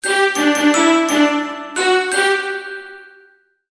Game-over-sound.mp3